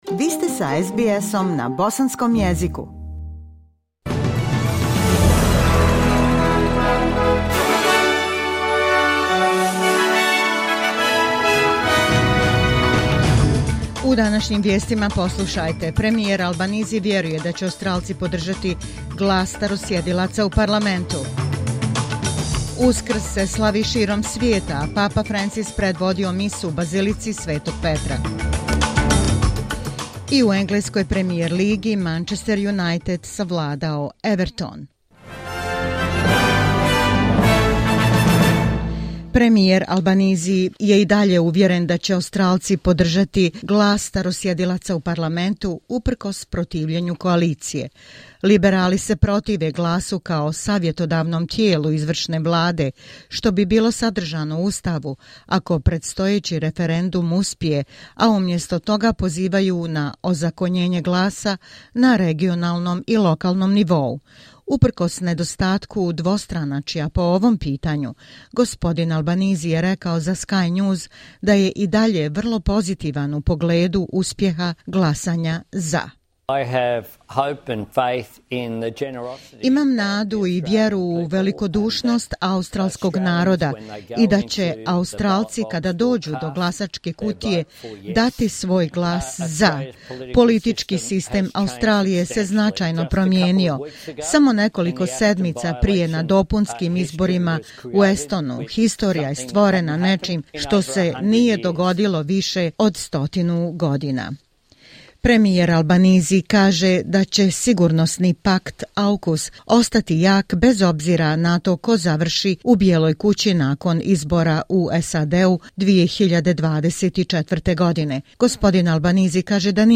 SBS news in the Bosnian language.